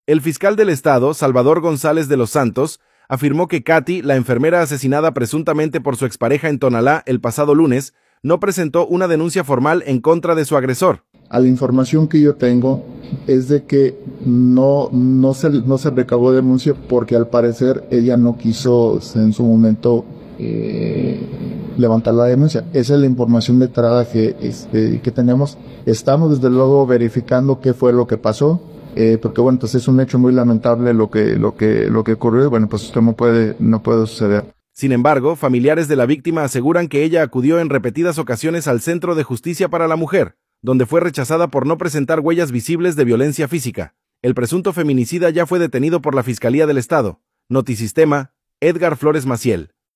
audio El fiscal del estado, Salvador González de los Santos